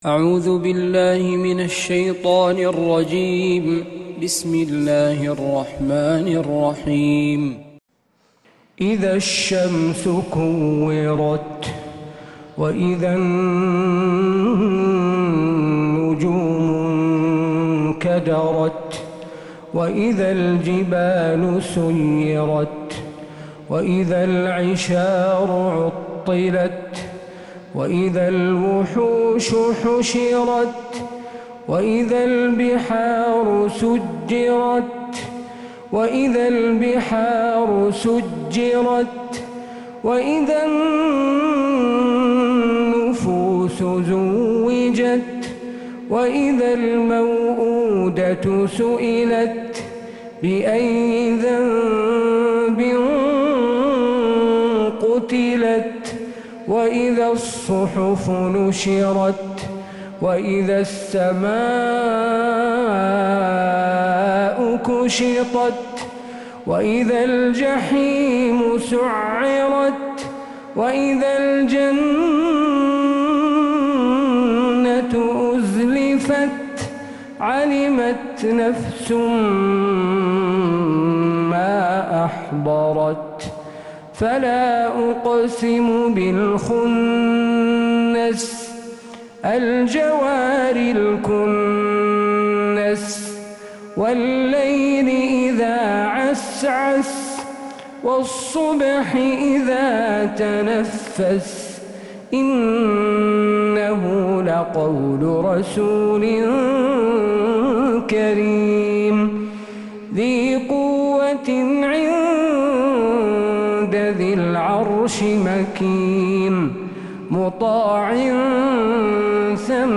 فجريات الحرم النبوي